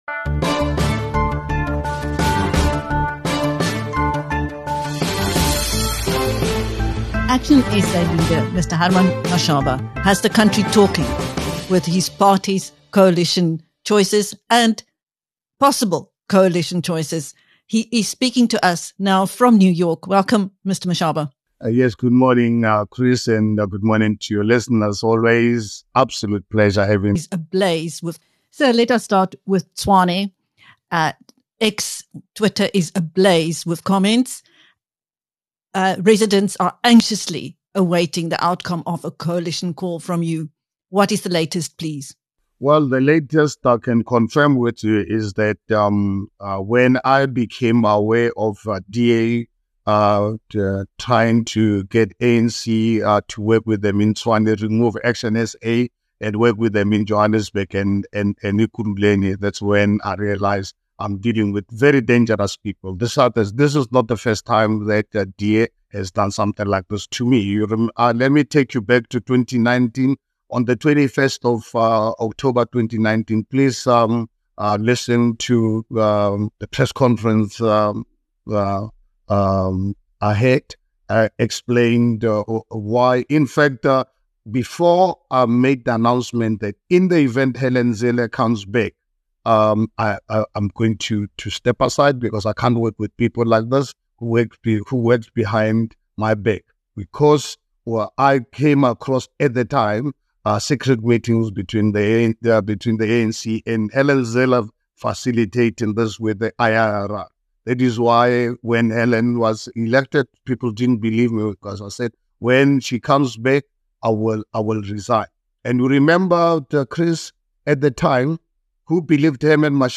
Speaking to BizNews from New York, Mashaba describes how - when he became aware of the Democratic Alliance (DA) trying to get the ANC to work with it in Tshwane, and remove ActionSA, he realised he was dealing with “very dangerous people”. On the other hand, he says the ANC is committed to the two parties holding each other accountable.